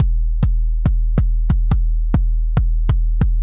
描述：音轨在Reason 3.0 Subtractor和Redrum中生成。低音鼓使用正弦波和三角波的组合。在140bpm下播放。
Tag: 140BPM 低音 bassdrum 碎拍 舞蹈 循环 冲击 固体 TECHNO